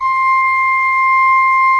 Index of /90_sSampleCDs/Propeller Island - Cathedral Organ/Partition L/ROHRFLUTE MR